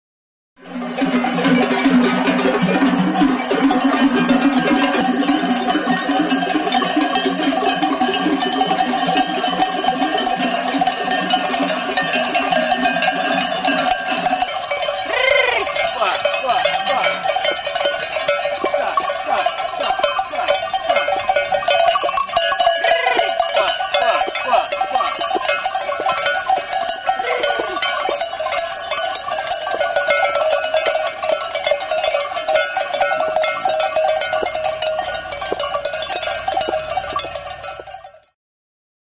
Music instrumentsESKILA; PIKETE
Idiophones -> Struck -> Indirectly
Faltzesko artaldearekin transumantzia.
Sekzio sasi errektangularrra duen ezkila da.
Larruzko zintarekin lotutako ezpelezko mihia du.